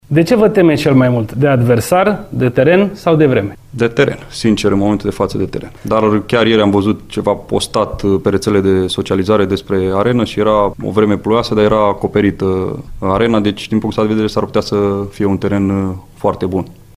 Selecţionerul echipei naţionale de fotbal a României, Mirel Rădoi, a declarat, duminică, într-o conferinţă de presă online, că se teme mai mult de un teren impracticabil decât de reprezentativa Islandei în partida directă programată la Reykjavik în semifinalele play-off-ului de calificare la Campionatul European de anul viitor.